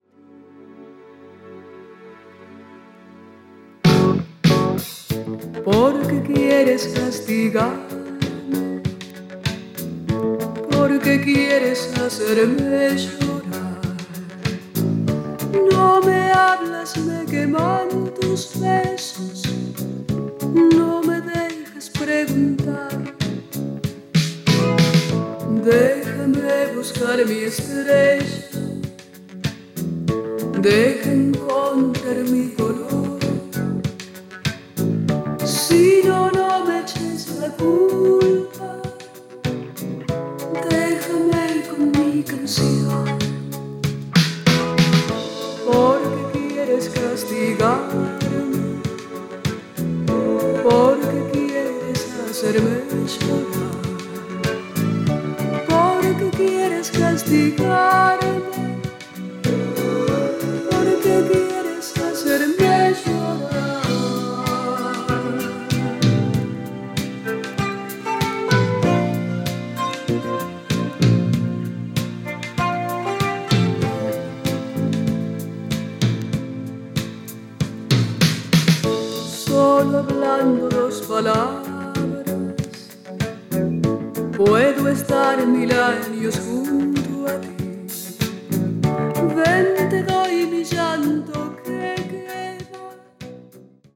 アルゼンチン・シンガー